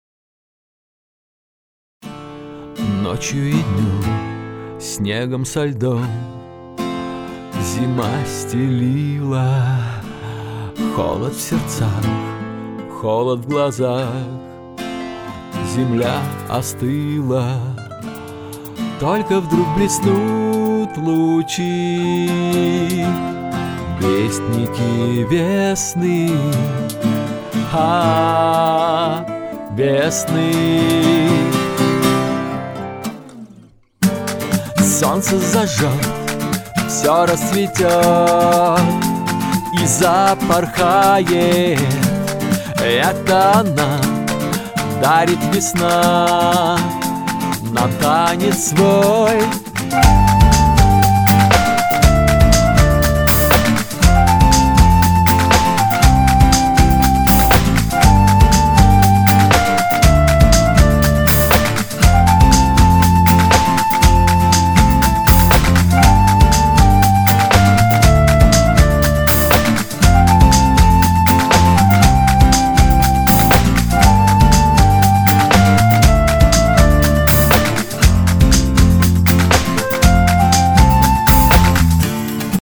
Собственно, вот один из моих черновиков - композиция под названием "Весна". Гитары софтовые вперемешку с живыми, если имеет значение.
Сведение, понятно, оценивать не нужно, его здесь, практически, нет.